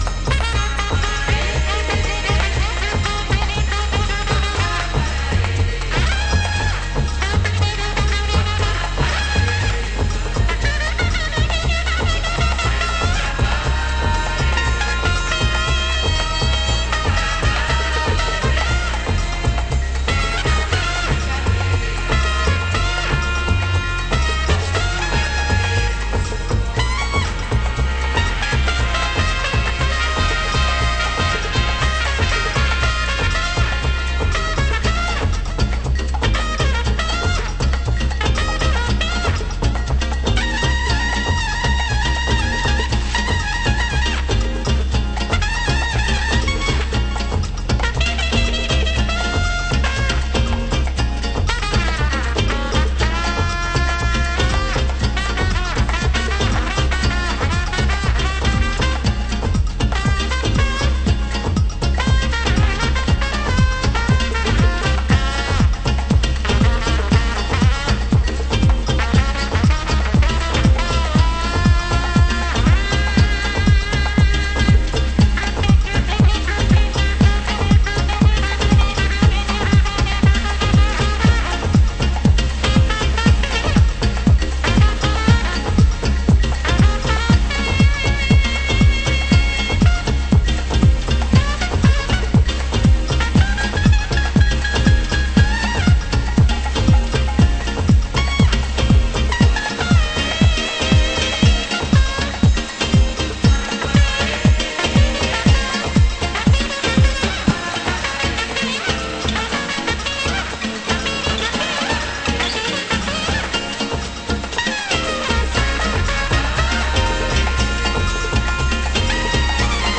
HOUSE MUSIC
Acoustic Mix